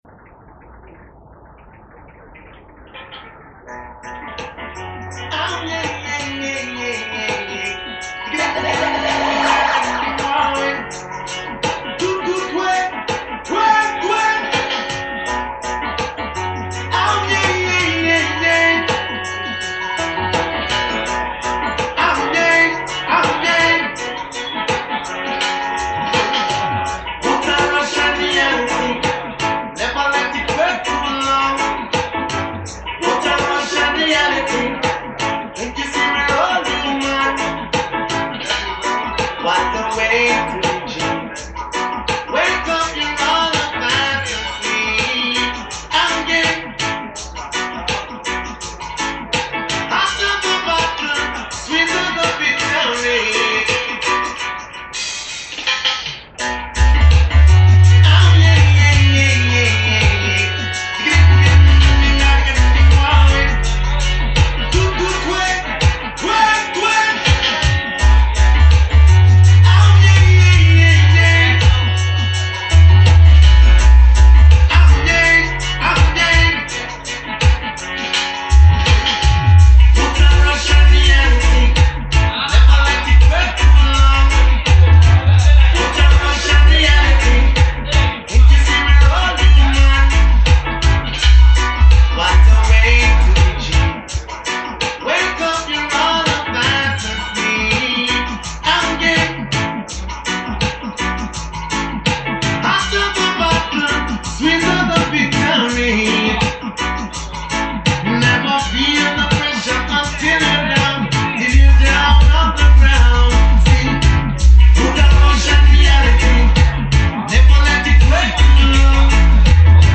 Many crews from Ital-y and special guests from UK.